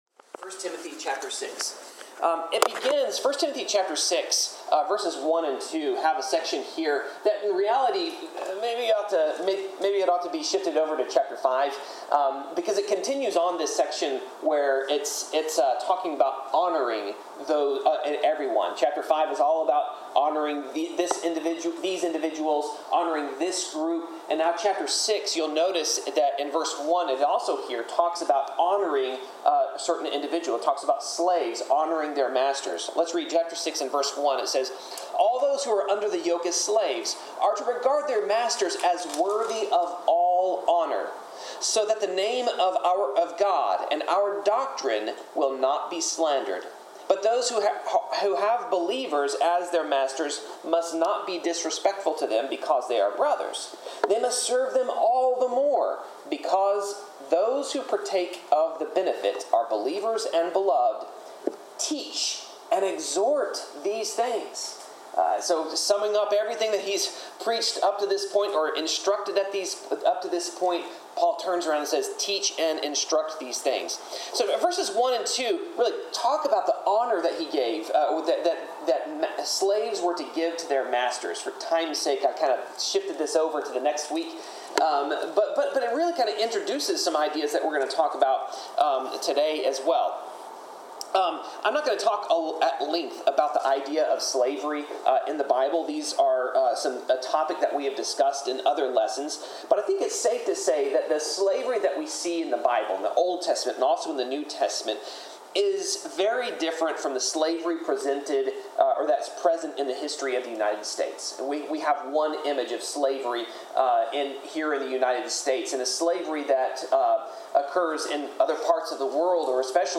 Service Type: Sermon